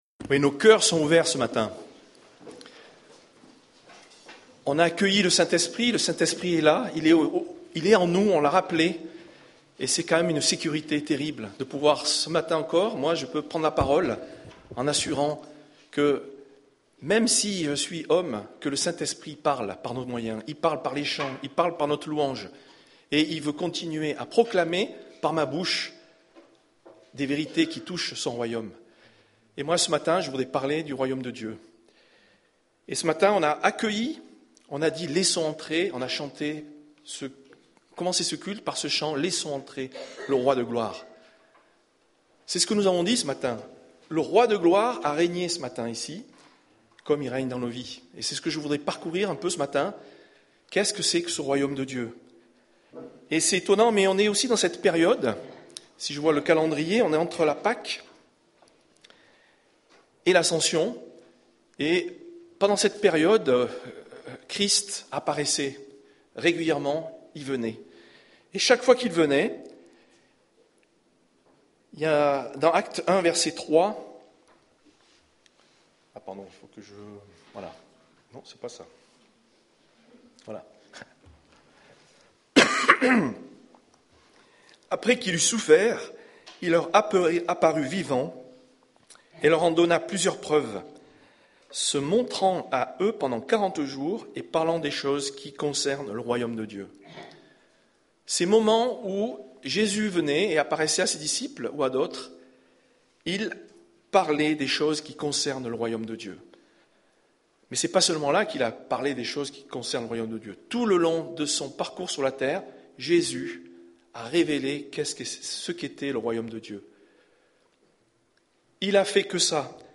Culte du 17 avril